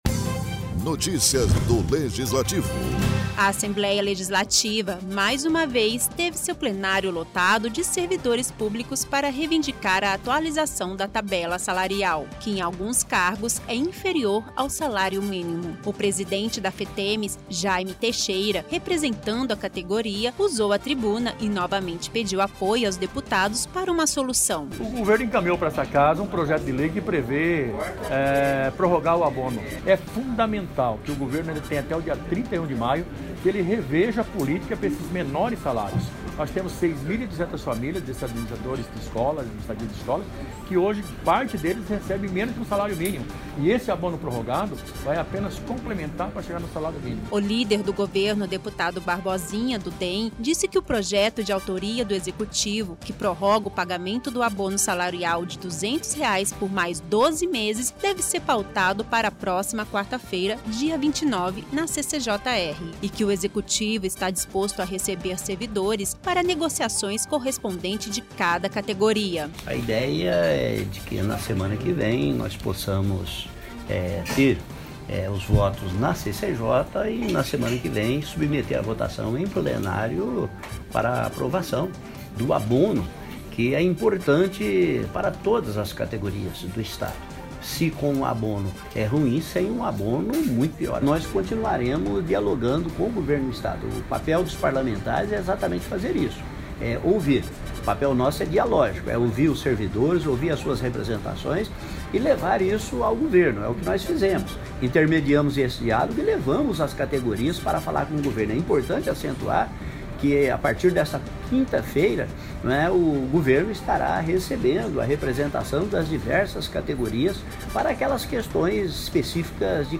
Texto e Locução: